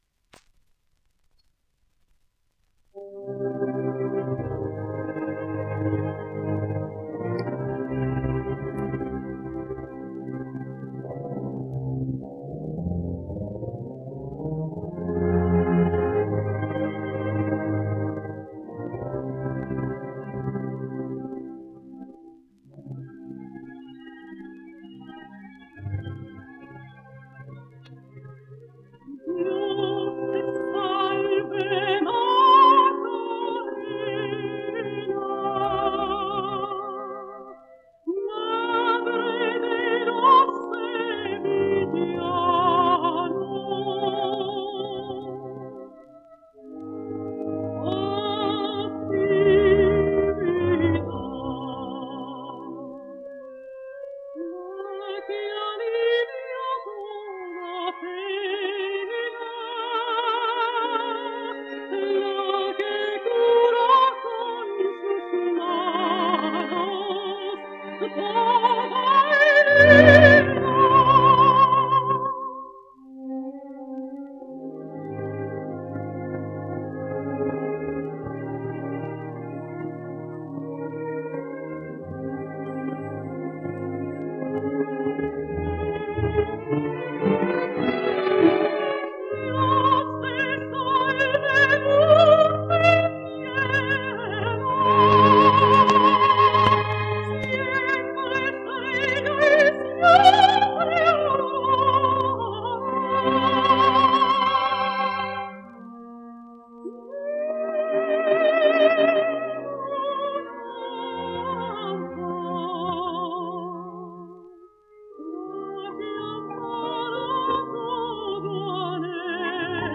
Saeta (sonido remasterizado)